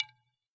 可乐瓶" 04
描述：用接触麦克风和迷你光盘录音机探索一个可乐瓶。将瓶盖掉在木地板上。
标签： 可乐瓶 非接触式麦克风 掉线 现场记录
声道立体声